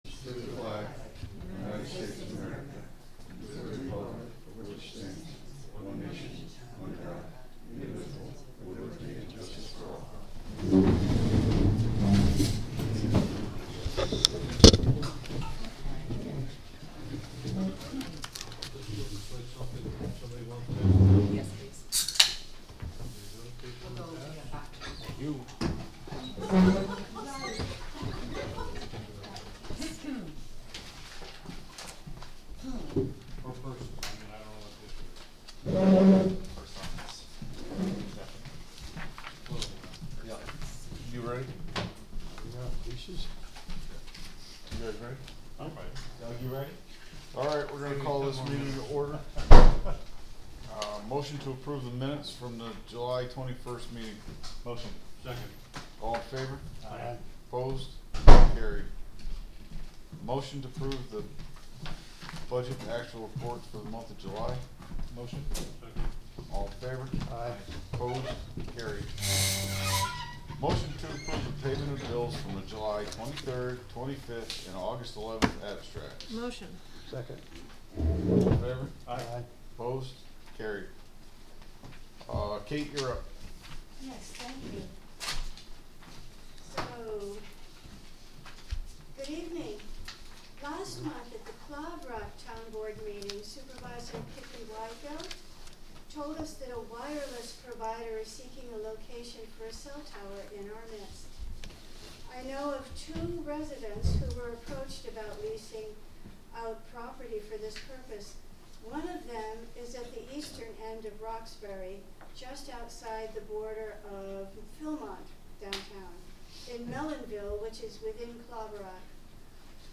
Live from the Village of Philmont